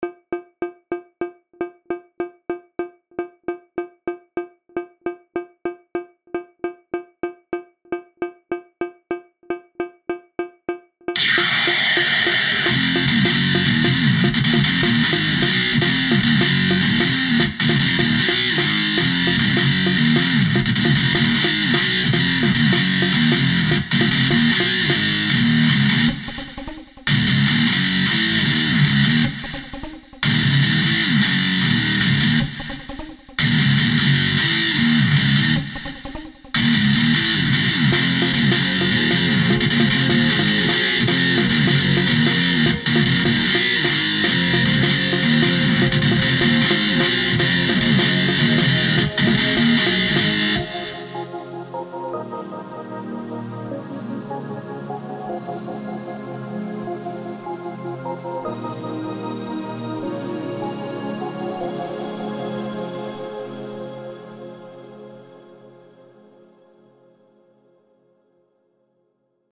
While it is an original and interesting take on the theme...
i didn't add the drum yet